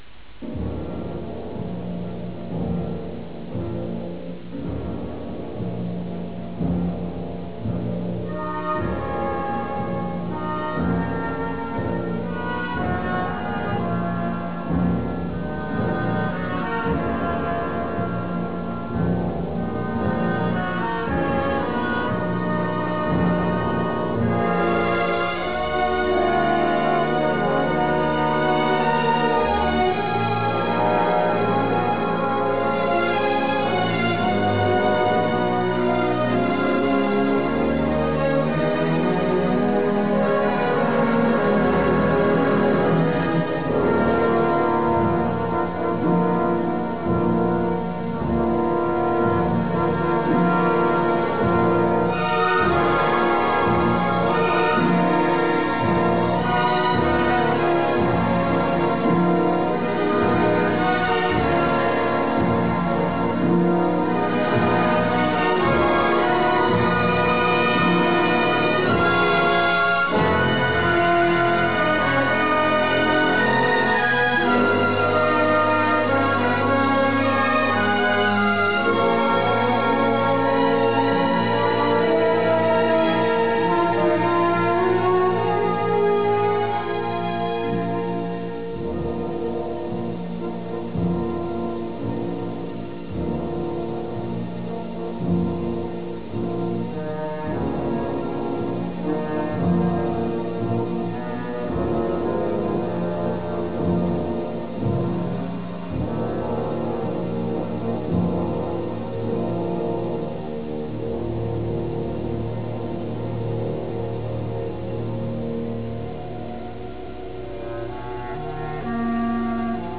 Imponente score musicale
Track Music